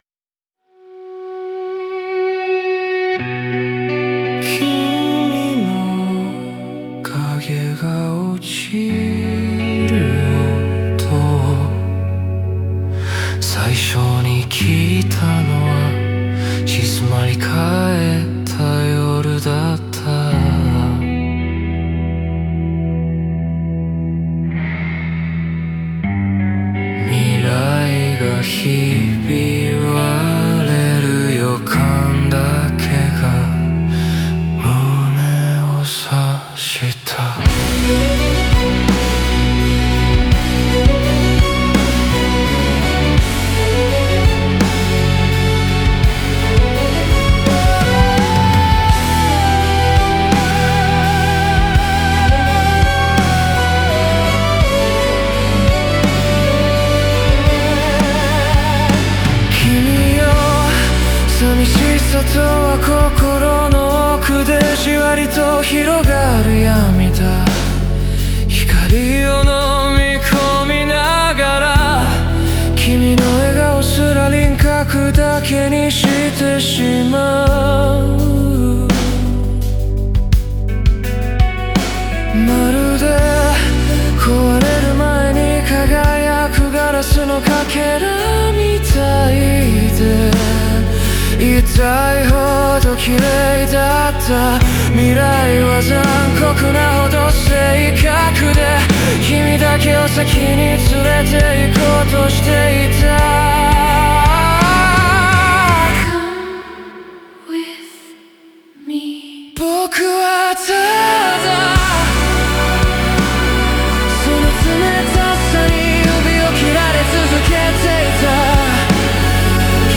静から動へ、そして再び静寂へと戻る曲線が、避けられない喪失の重さを描き出す。